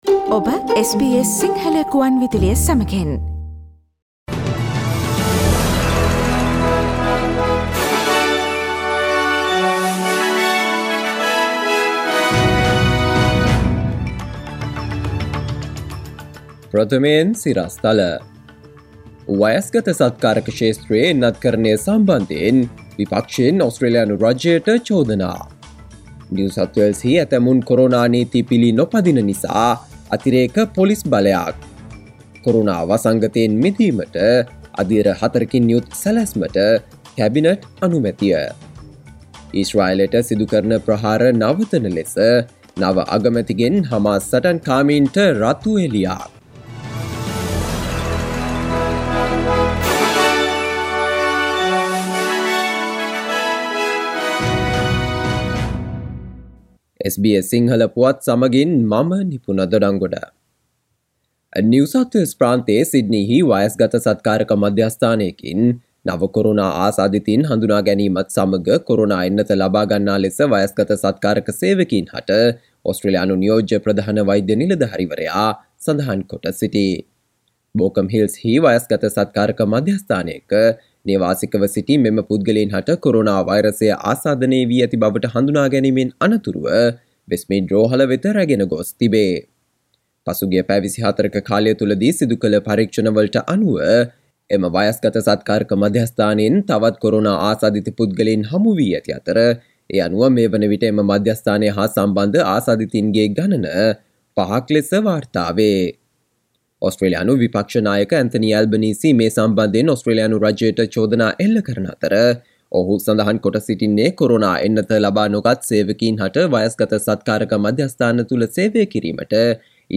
ජූලි 5දා SBS සිංහල ප්‍රවෘත්ති: NSW කොරෝනා පොකුර පැතිරෙන විට එන්නත් ගැන විපක්ෂයෙන් මොරිසන් රජයට චෝදනා